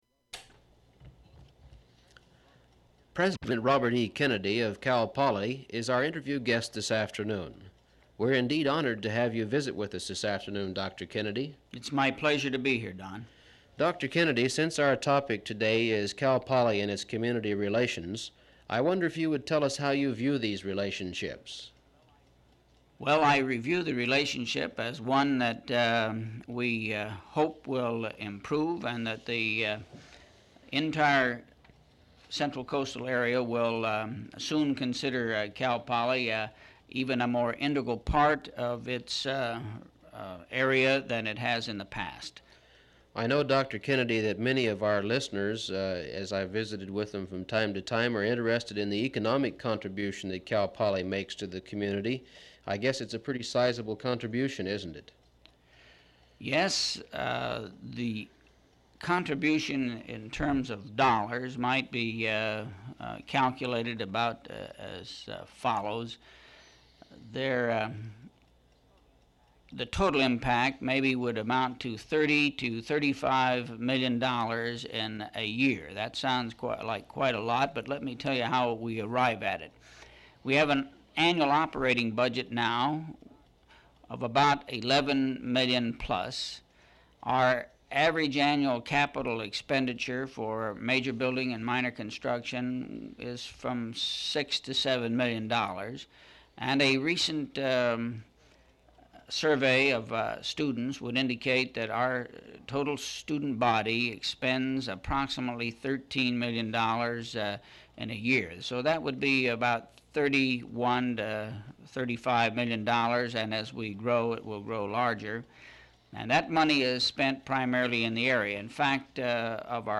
Interview
• Open reel audiotape